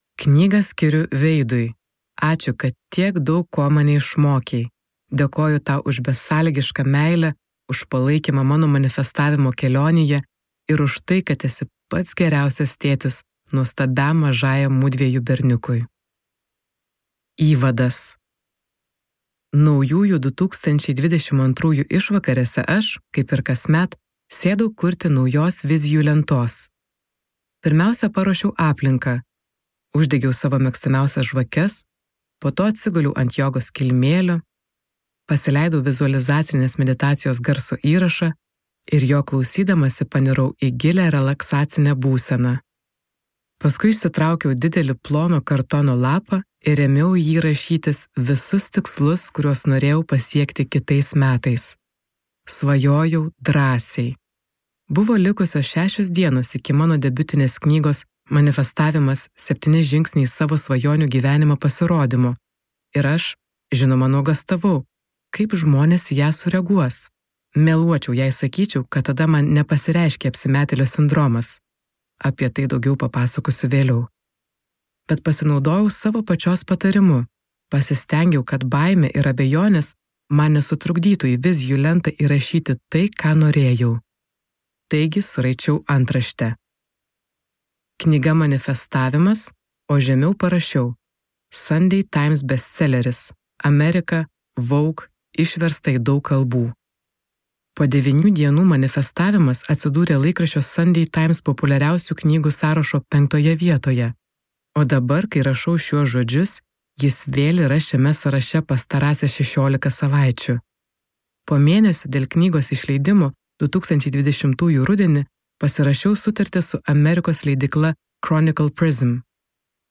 Audio Manifestavimas. Nerkite giliau
Skaityti ištrauką play 00:00 Share on Facebook Share on Twitter Share on Pinterest Audio Manifestavimas.